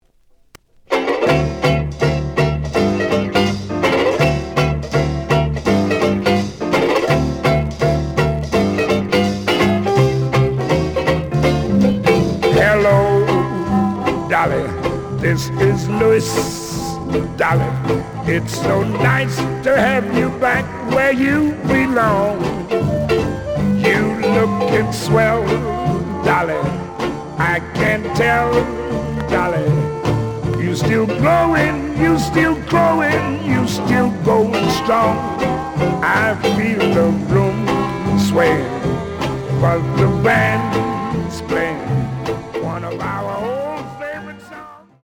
The audio sample is recorded from the actual item.
●Genre: Vocal Jazz
Some noise on A side.